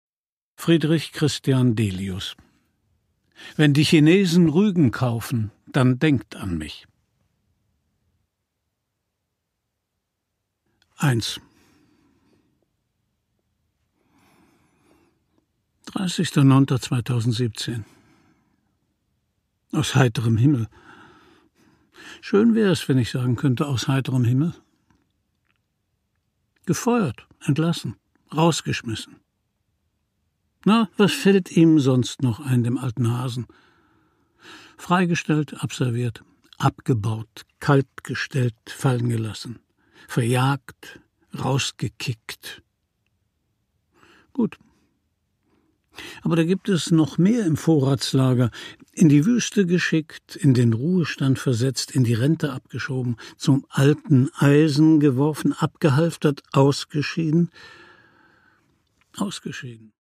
Produkttyp: Hörbuch-Download
Gelesen von: Christian Brückner